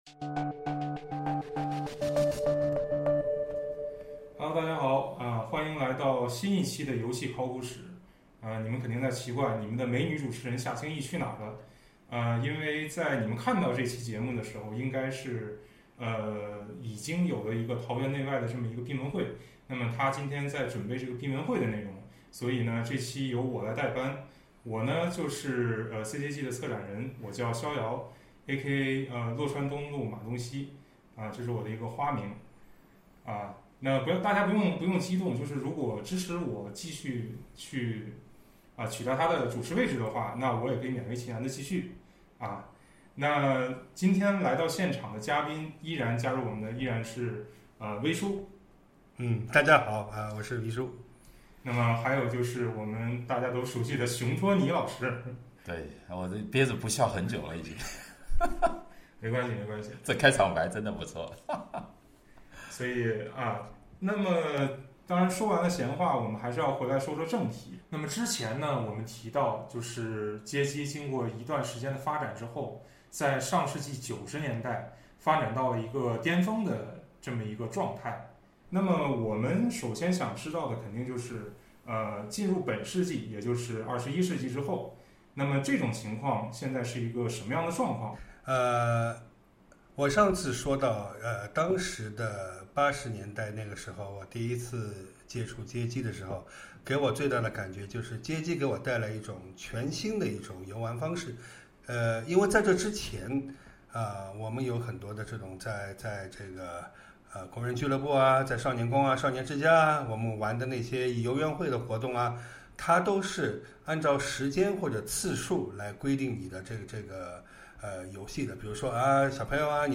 游戏考古史VOL.4：街机游戏的衰落与自救对话（行业转型篇）